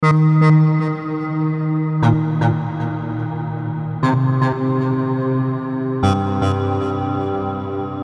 Neon Pulse 120 BPM Analog Sequence
Experience a driving analog synth loop with a warm, evolving filter and wide reverb that creates a cinematic space.
Genres: Synth Loops
Tempo: 120 bpm
Neon-pulse-120-bpm-analog-sequence.mp3